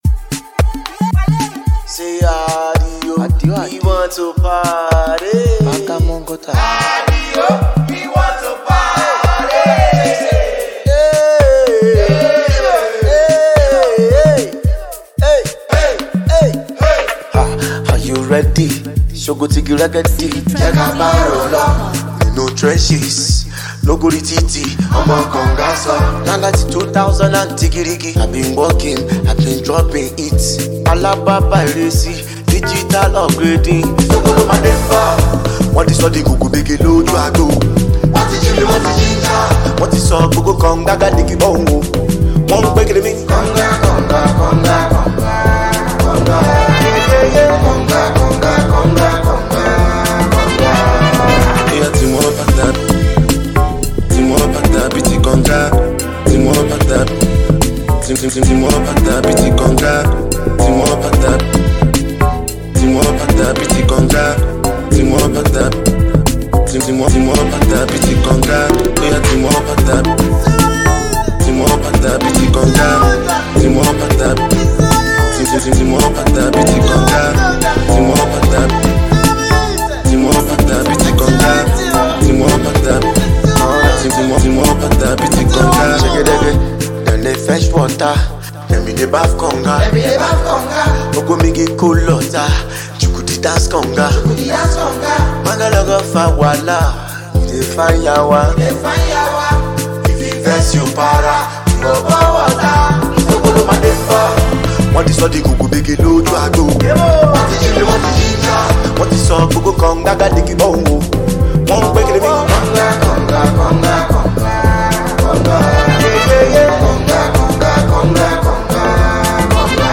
(Party/Household Banger)